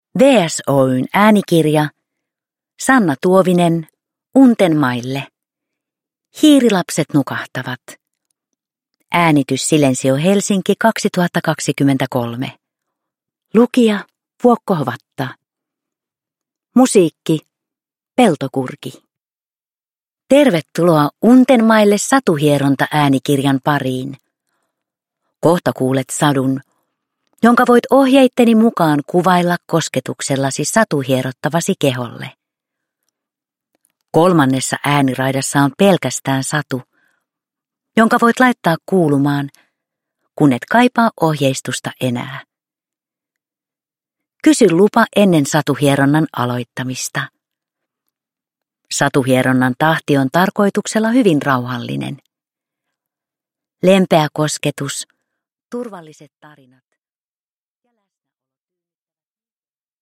Untenmaille - Hiirilapset nukahtavat – Ljudbok – Laddas ner
Satuhieronta-sadut vievät kokemaan untuvaista suloista pehmeyttä, metsän rauhoittavia ääniä sekä turvallista kotipesän tunnelmaa.
Untenmaille äänikirjat sisältävät teoksia varten sävelletyn rauhoittavan ja elämyksellisen musiikillisen äänimaiseman.